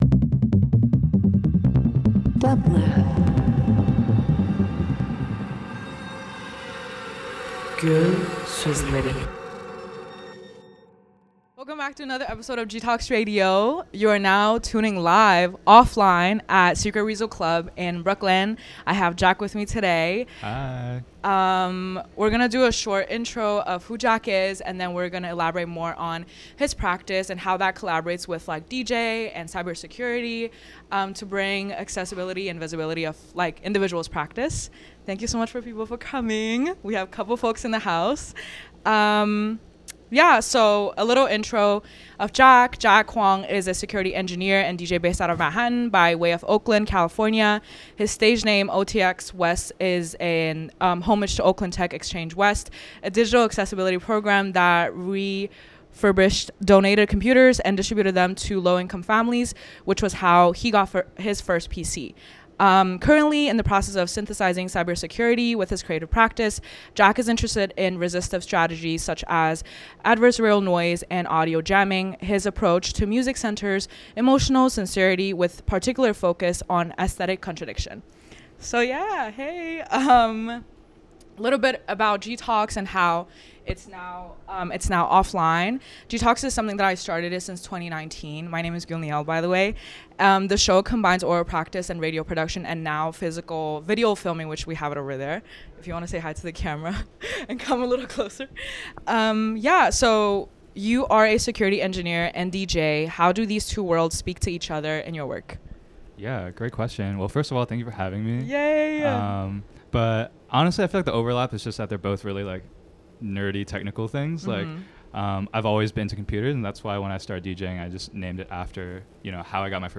Beats Dance Electronic hyperpop Interview